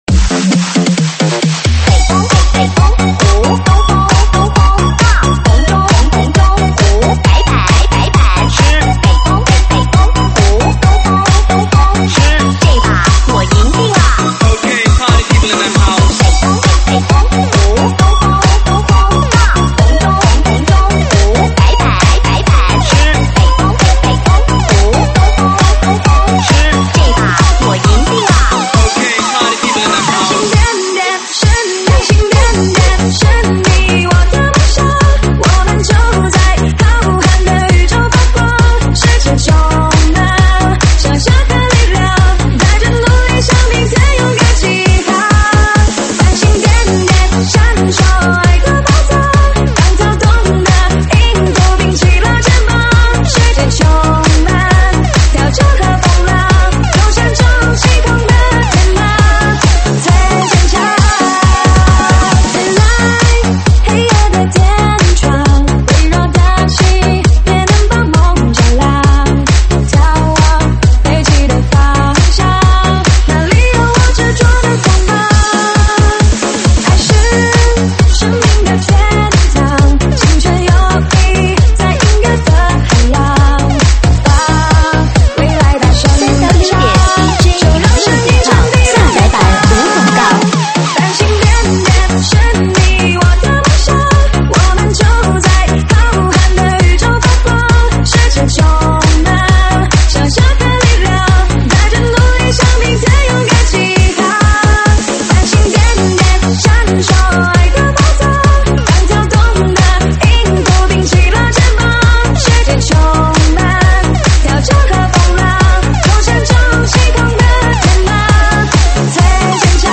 现场串烧